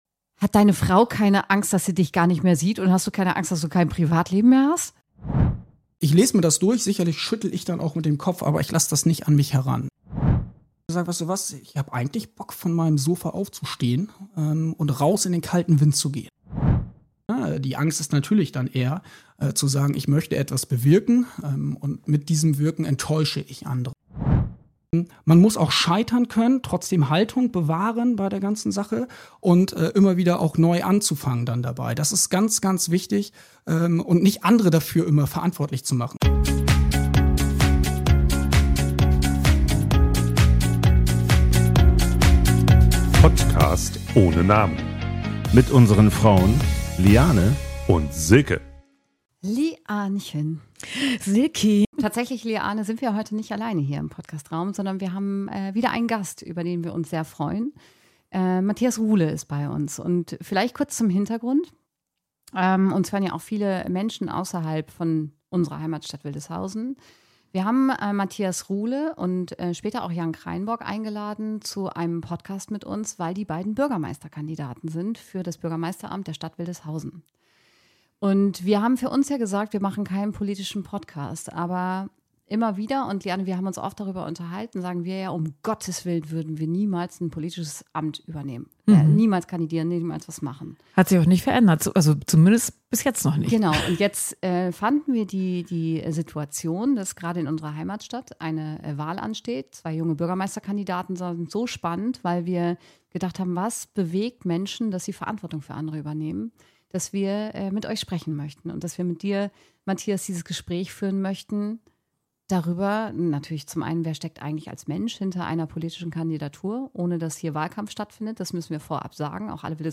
Was verändert sich im eigenen Leben, wenn man kandidiert? Wie geht man mit öffentlicher Kritik, Erwartungen und Druck um? Ein persönliches Gespräch über Motivation, Zweifel und die Frage, warum Wählen mehr ist als ein Kreuz auf dem Stimmzettel.